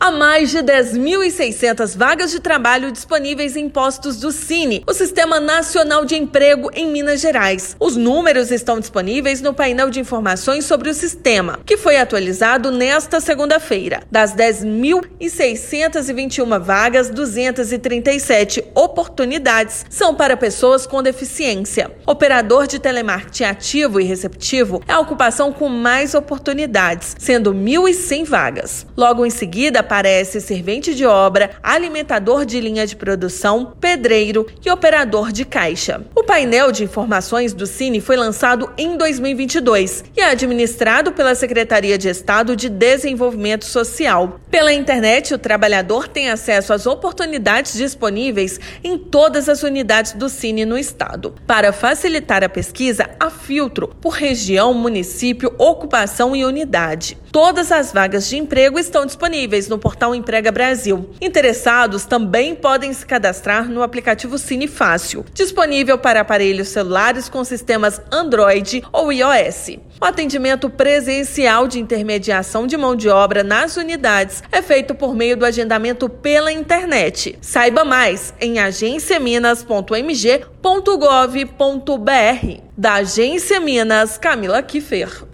Unidades de Uberlândia, Araxá e Belo Horizonte são as que concentram o maior número de oportunidades nesta segunda-feira (12/6). Ouça matéria de rádio.